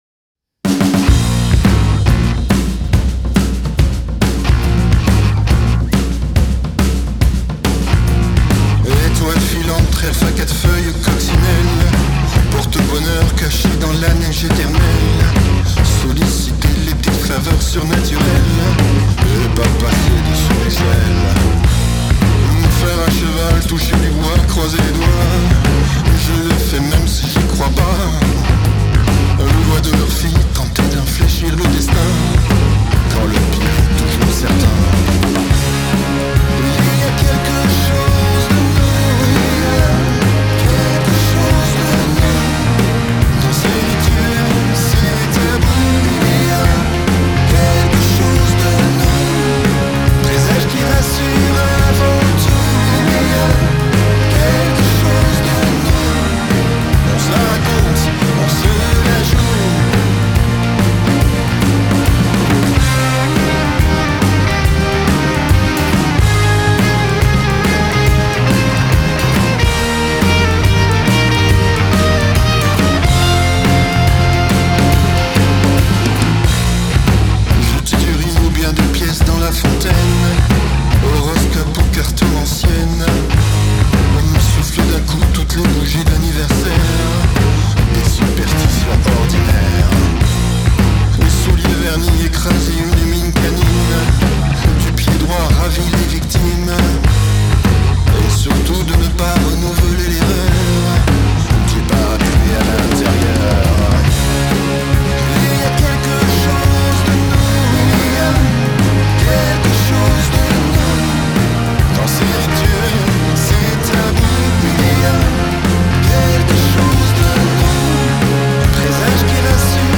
Musique NEO-RETRO
Un rockabilly explosif.
Puissance des guitares et groove de la voix.